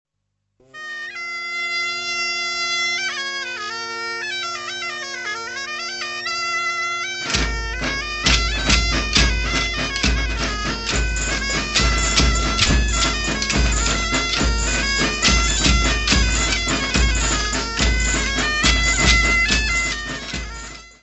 Folclore português : Trás-os-Montes e Alto Douro
Grupo Folclórico Mirandês de Duas Igrejas
Palombas (Lhaço andante das Procissões e Cortejos).